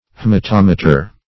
Meaning of haematometer. haematometer synonyms, pronunciation, spelling and more from Free Dictionary.
Search Result for " haematometer" : The Collaborative International Dictionary of English v.0.48: Haematometer \H[ae]m`a*tom"e*ter\ (-t[o^]m"[-e]*t[~e]r), n. [H[ae]mato- + -meter.]